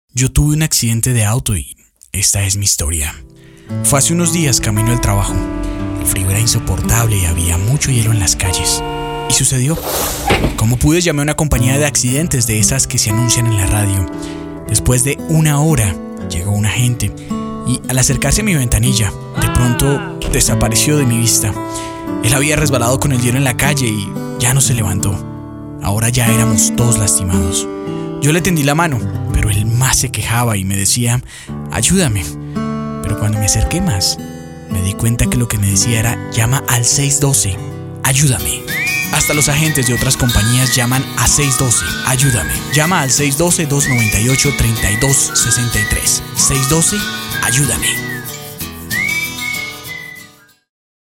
voz con alta Versatilidad vocal; registros altos o bajos todo deacuerdo al cliente junto a la identidad del proyecto y la marca.
Sprechprobe: Sonstiges (Muttersprache):